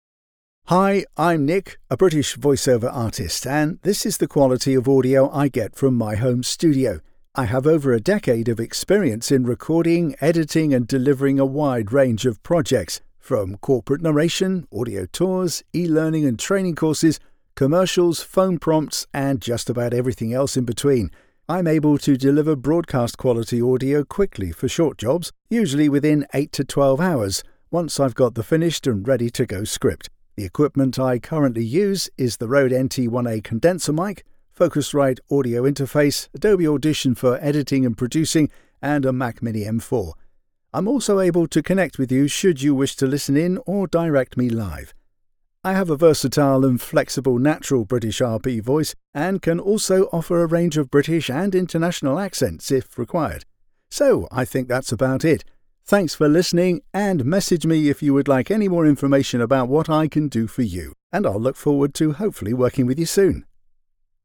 Male
English (British)
Older Sound (50+)
With over a decade of experience, I have a warm, trustworthy, and versatile British male voice with a natural RP accent.
Broadcast-quality audio from a professional home studio with fast turnaround and seamless delivery.
Studio Quality Sample
Intro - Studio Sample